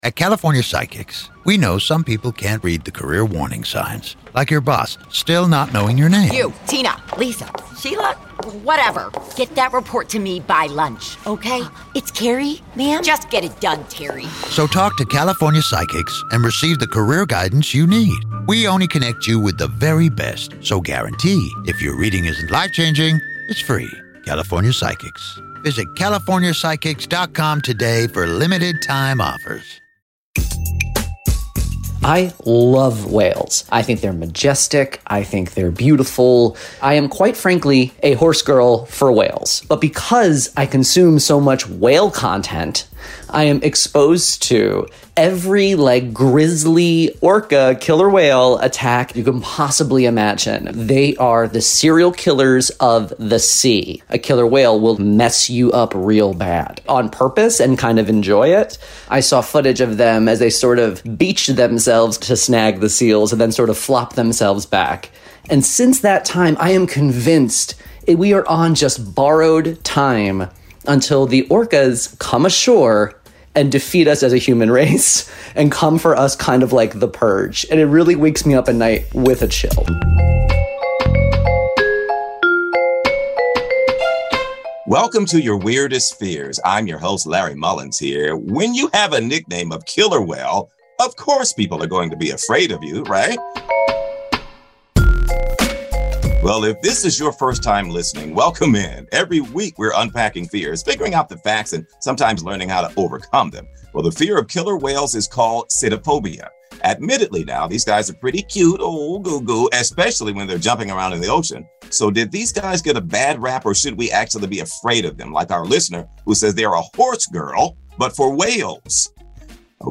sits down with orca expert